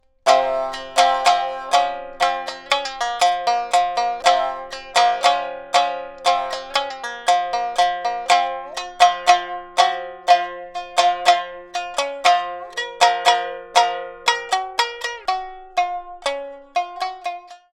• Stimmung: Sansagari
Sansagari: C-F-B